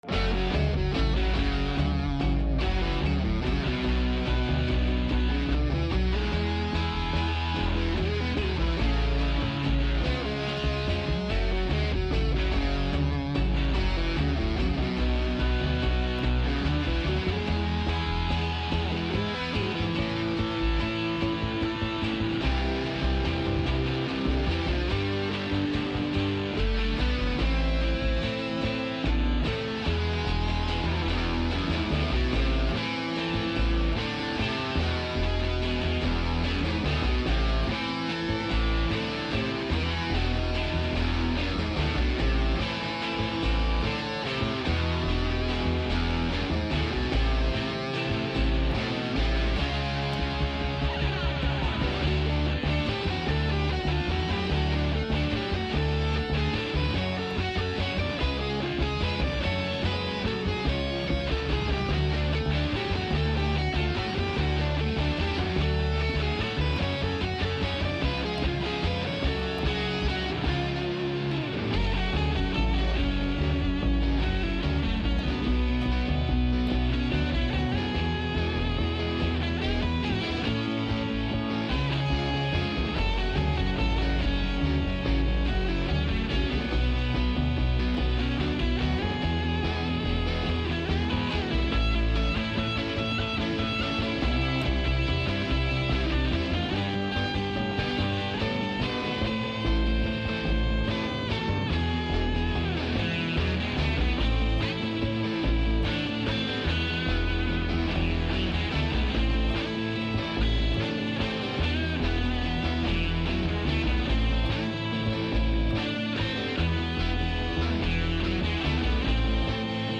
Συνέντευξη με τον Μπάμπη Παπαδόπουλο για τη συναυλία του Σαββάτου 24/1/2026 στην Μικρή Σκηνή.